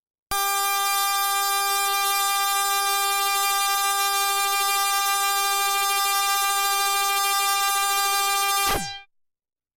标签： FSharp4 MIDI音符-67 雅马哈-CS-30L 合成 单注 multisam PLE
声道立体声